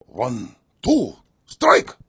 zen_onetwostrike2.wav